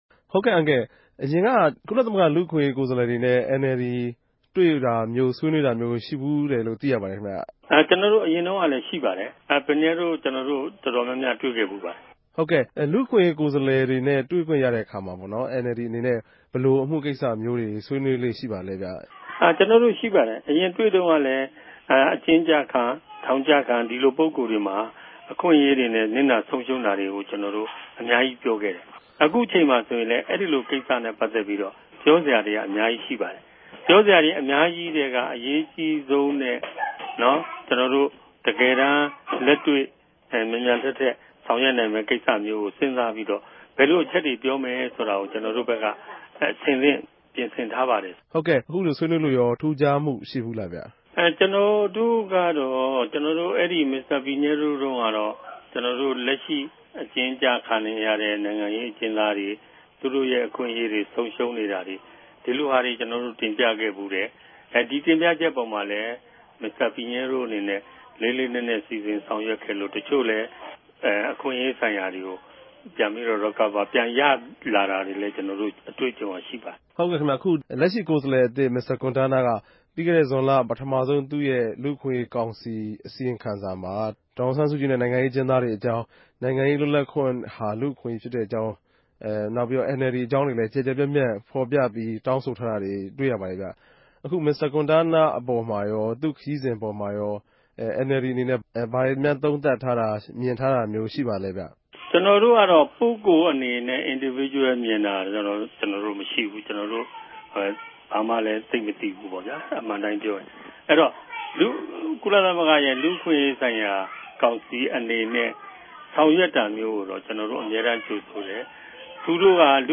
ဆက်သြယ် မေးူမန်းခဵက်။